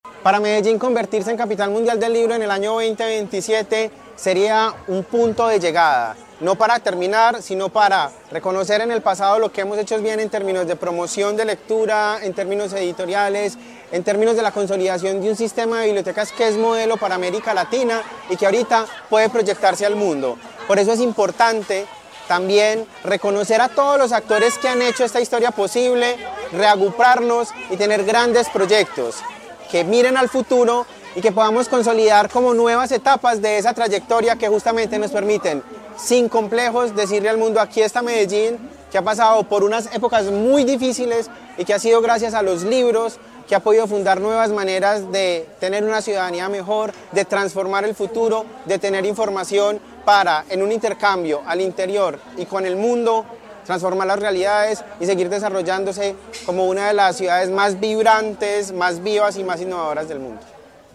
Palabras de Carlos Andrés Quintero Monsalve, director del DAGRD